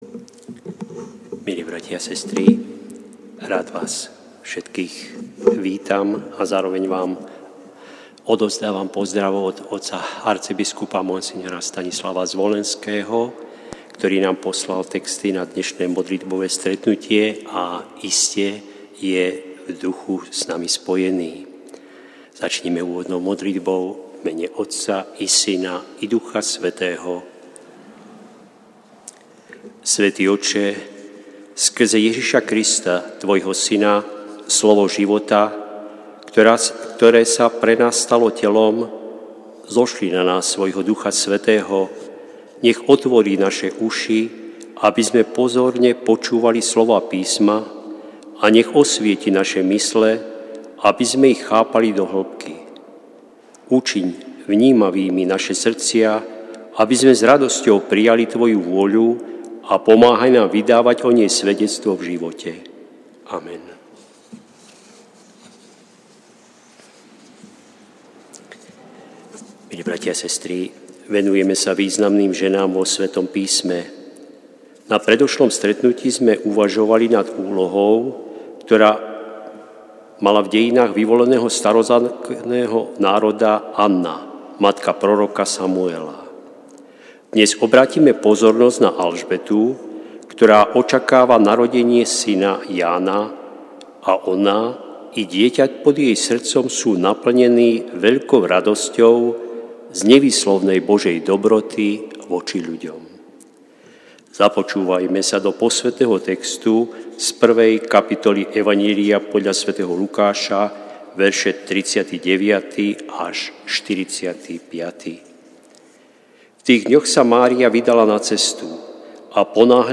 Prinášame plný text a audio záznam z Lectio divina, ktoré odznelo v Katedrále sv. Martina 4. februára 2026.